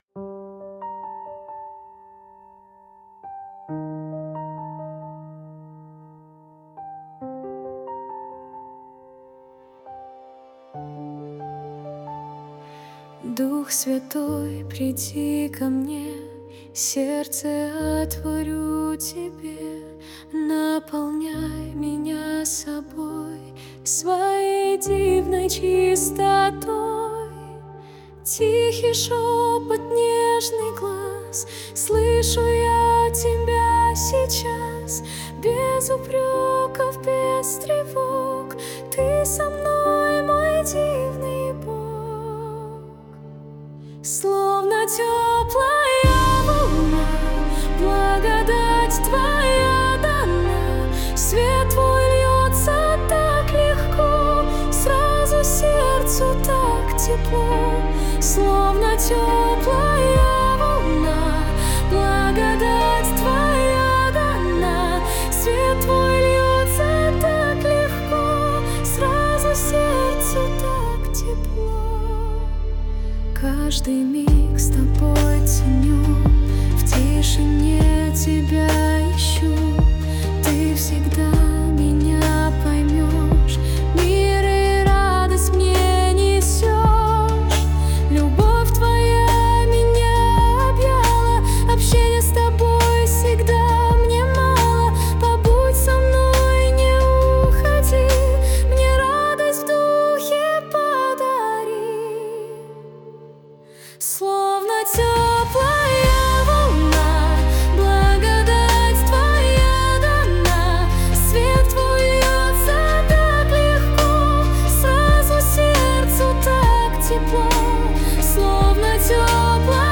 песня ai
262 просмотра 781 прослушиваний 82 скачивания BPM: 68